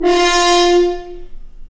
Index of /90_sSampleCDs/Roland L-CD702/VOL-2/BRS_F.Horns FX+/BRS_FHns Mutes